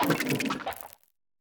Cri de Selutin dans Pokémon Écarlate et Violet.